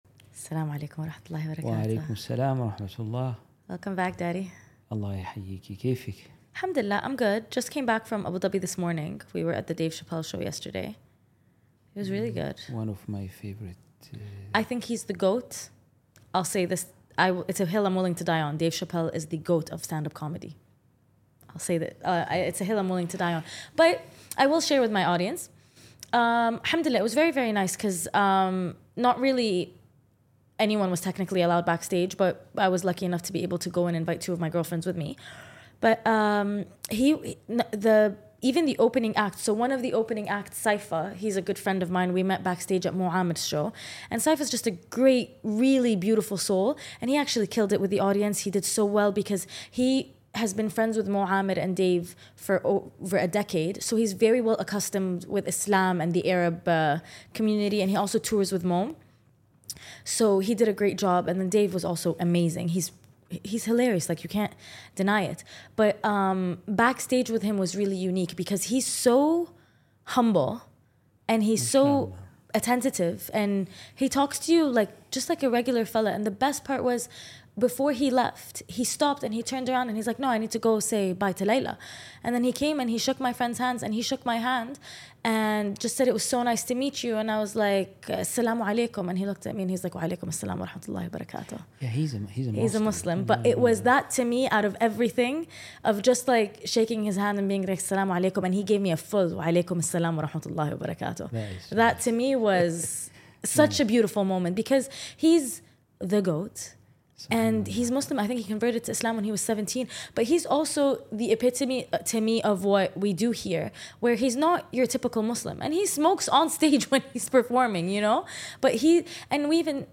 Play Rate Listened List Bookmark Get this podcast via API From The Podcast The premise of this podcast is that I am having a conversation with my conscience. That is the male voice you hear; my father. We tackle all things life by going through all the lessons I’ve learned with him as my mentor.